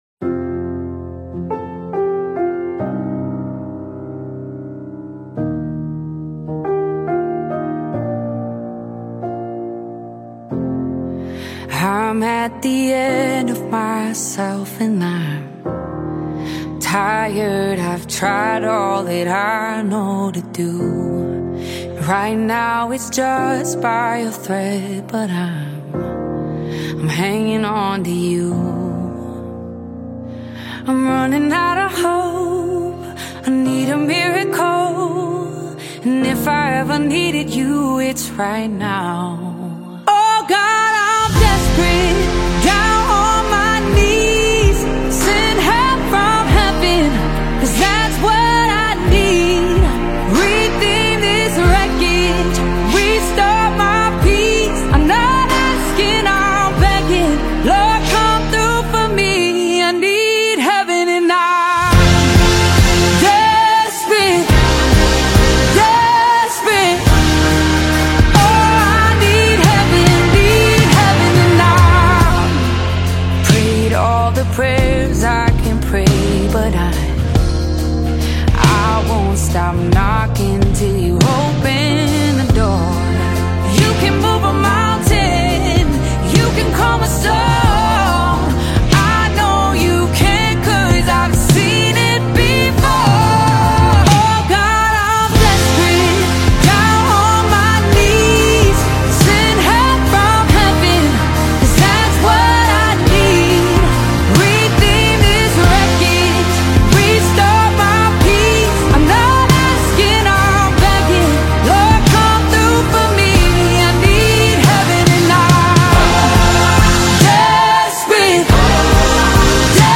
American Gospel Songs